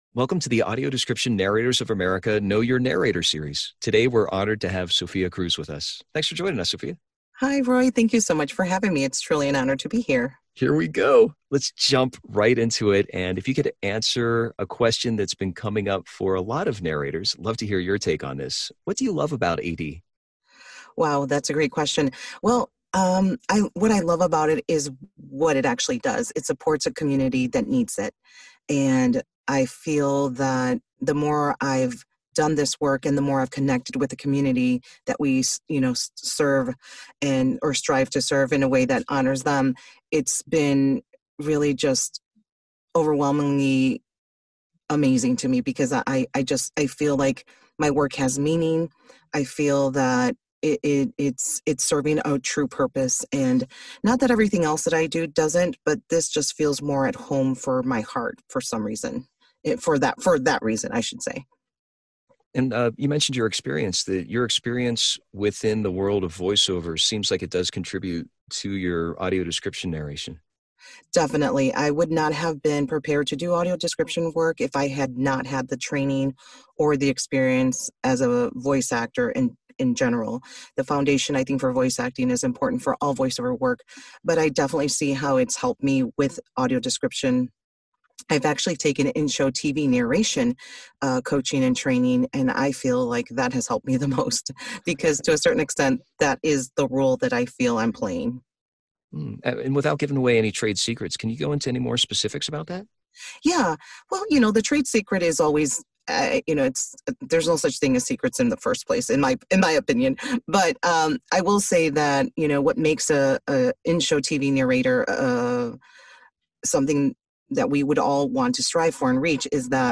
Audio Description Interview